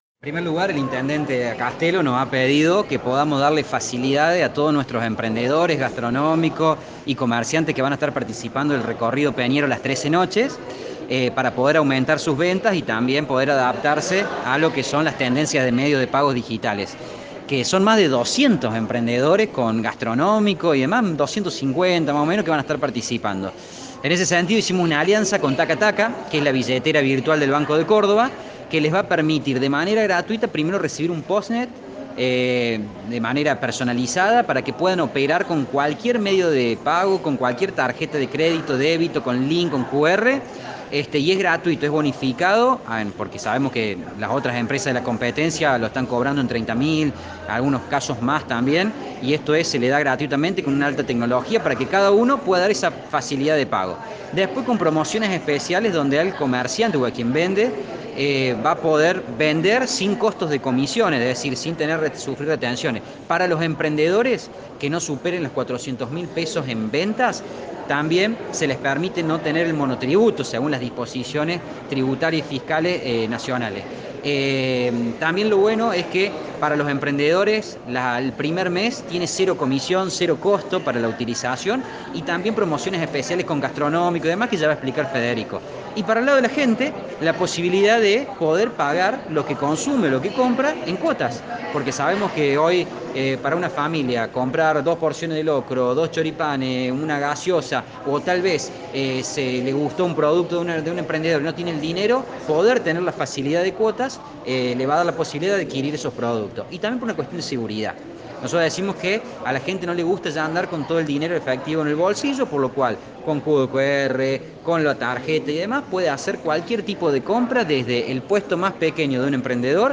Este ultimo junto al presidente del Concejo Deliberante, Juan Pablo Inglese, realizaron posteriormente una conferencia de prensa para brindar mas detalles acerca del acuerdo.